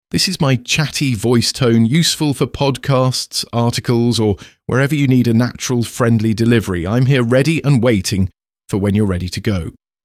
Male
English (British), English (Neutral - Mid Trans Atlantic)
Podcasting